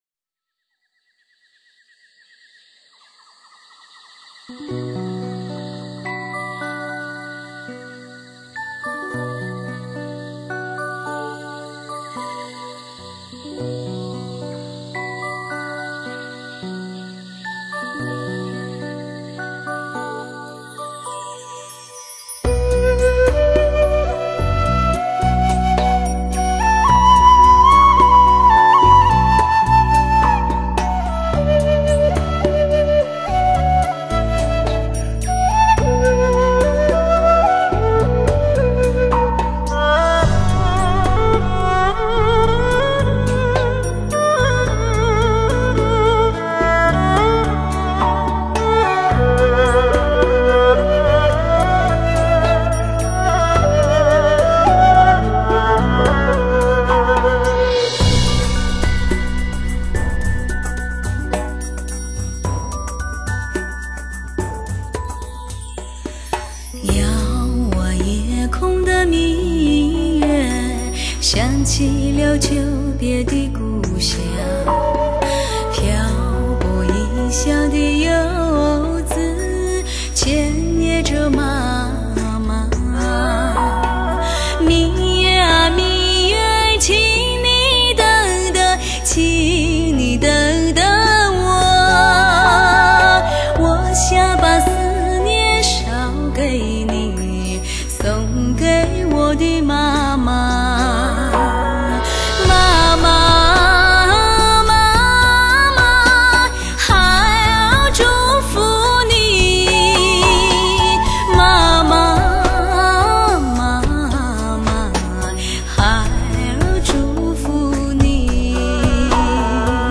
感觉西藏灵动而原始的旋律  走入古老民族心灵的深处......
感谢楼主  辛苦提供    西藏音乐，谢谢